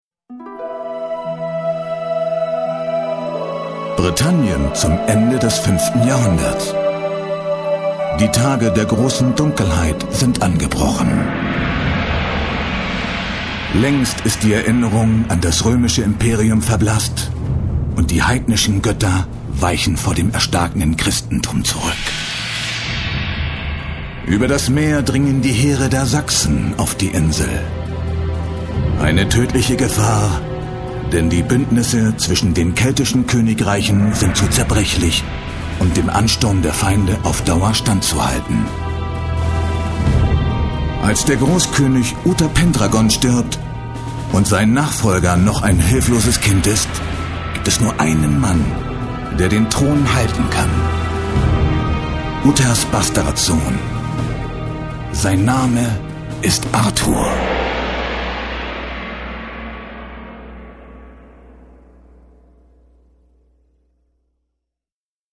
Kein Dialekt
Sprechprobe: Industrie (Muttersprache):
english (us) voice over artist